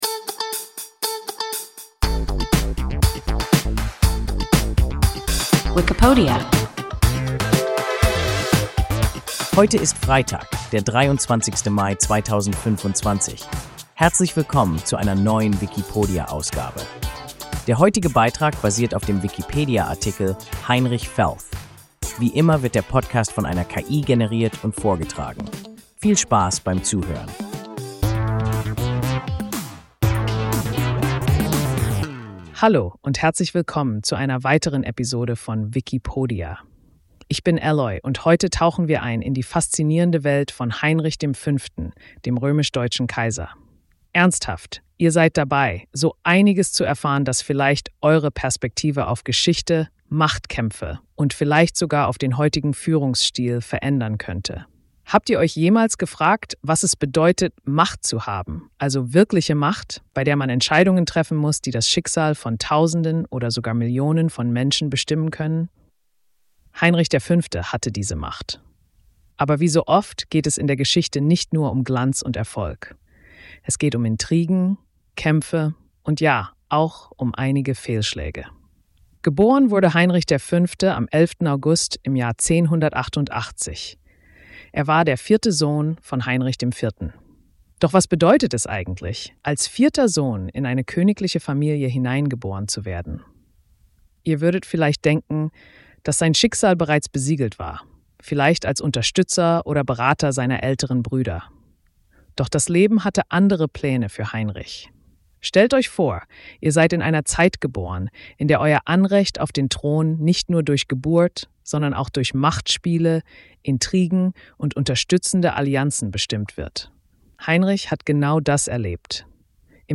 Heinrich V. (HRR) – WIKIPODIA – ein KI Podcast